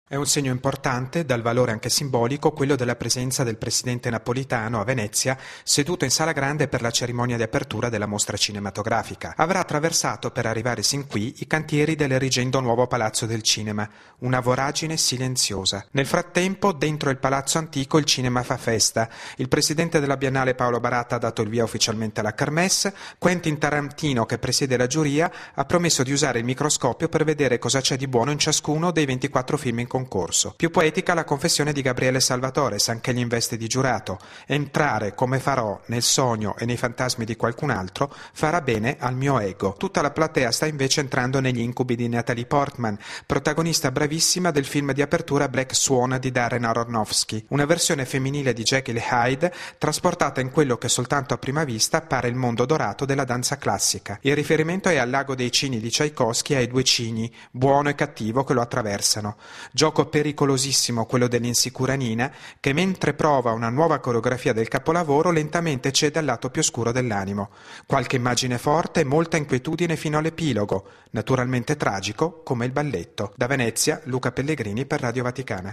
Al via a Venezia la Cerimonia di Apertura della 67ma Mostra Internazionale d’Arte Cinematografica: dopo l’omaggio a Vittorio Gassman a dieci anni dalla scomparsa, la kermesse parte ufficialmente con la solidissima presenza dei titoli italiani, un’ottima rappresentanza internazionale e per la prima volta un Presidente della Repubblica italiana all’inaugurazione del Festival. Da Venezia